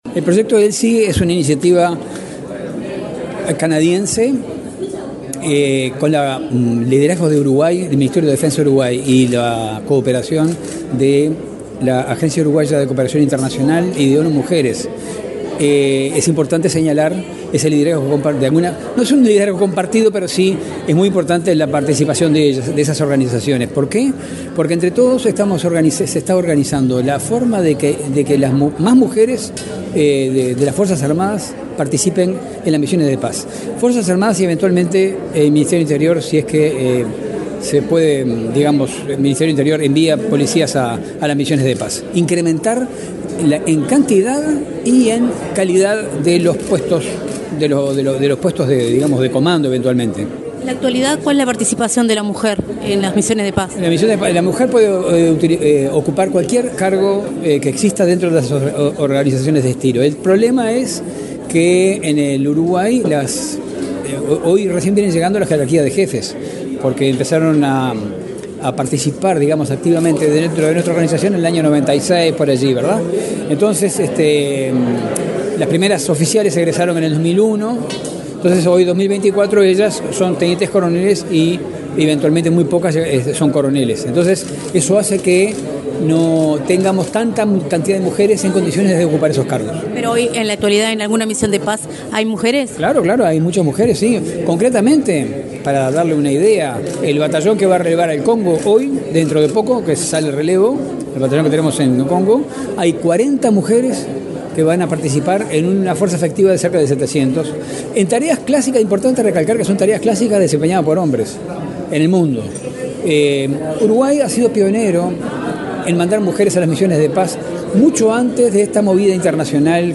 Entrevista al subsecretario de Defensa Nacional, Marcelo Montaner